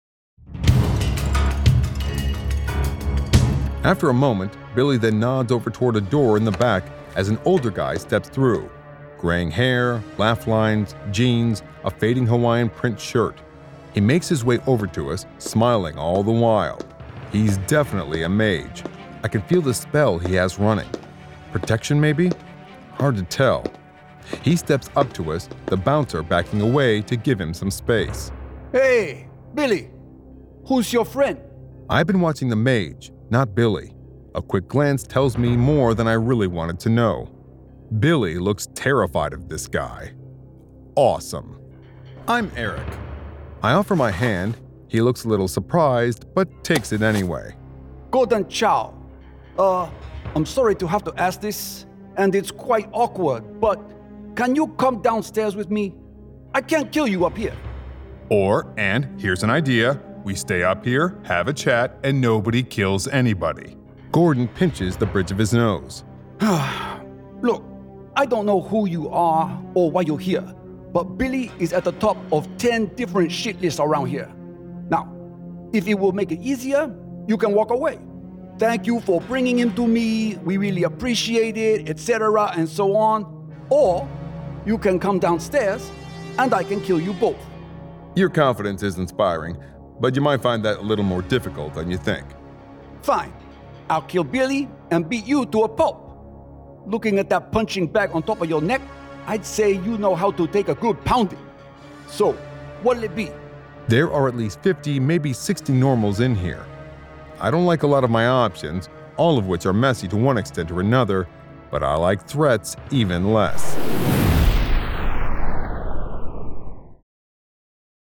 Full Cast. Cinematic Music. Sound Effects.
[Dramatized Adaptation]
Genre: Urban Fantasy